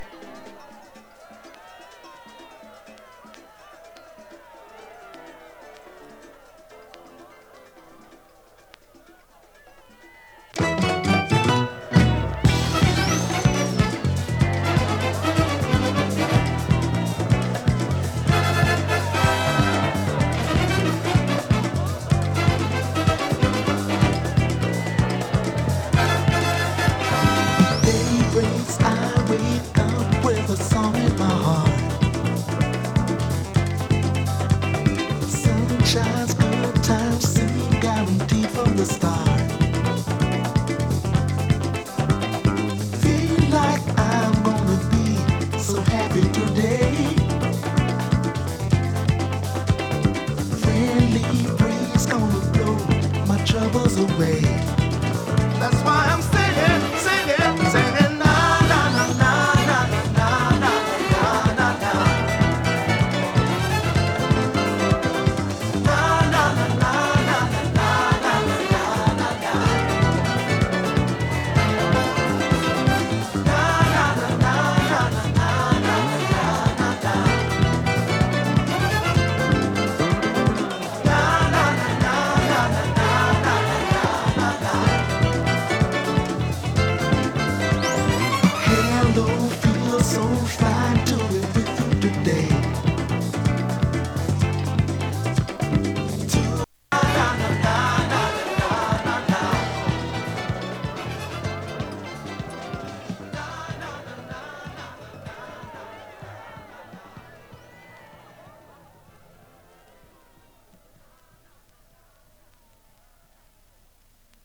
＊音の薄い部分で時折軽いチリパチ・ノイズ。